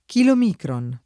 chilomicron [ kilom & kron ]